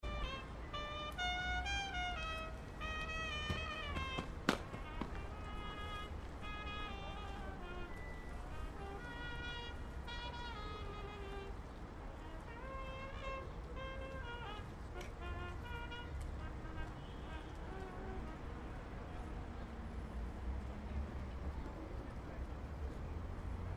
und irgendwo, vor einem einsamen Denkmal des Konsums, steht ein blinder Mann und spielt Trompete für die Geister der Vergangenheit.
Trompete
Trompete.mp3